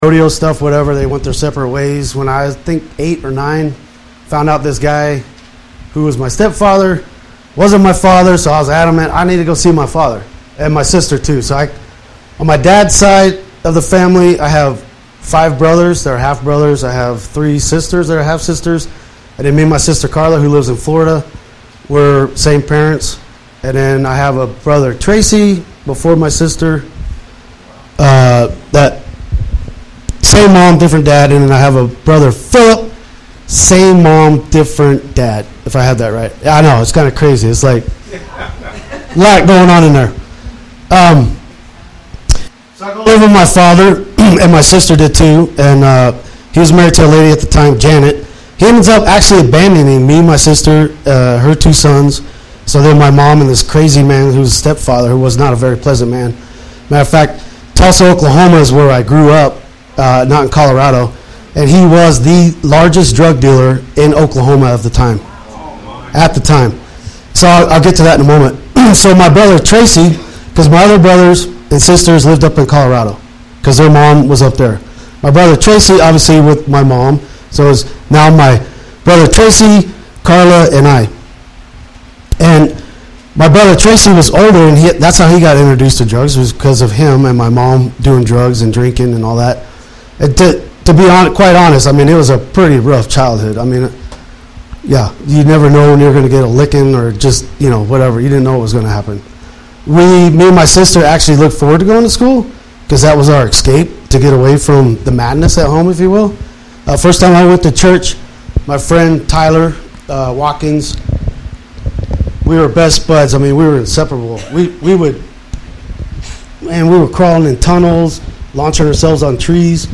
Missing the first 3 minutes.